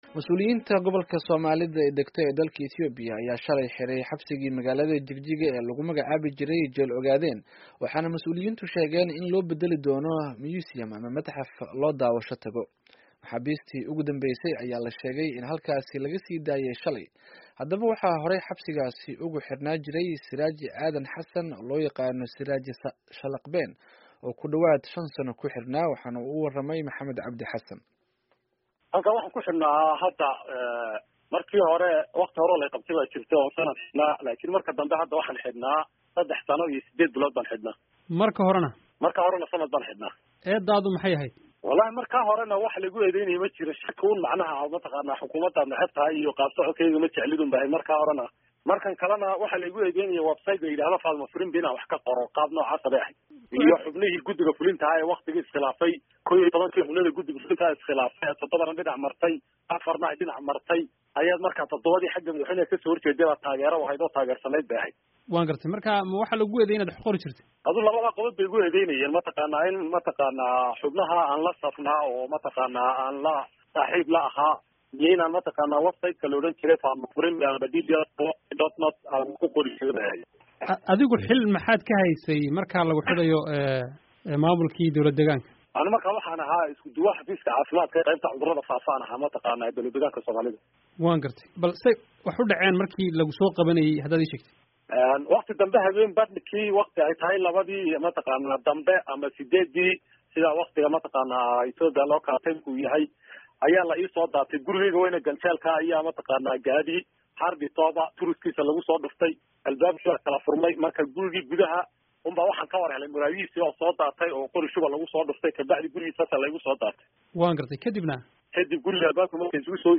Wareysi: Jeel Ogaadeen oo la Xiray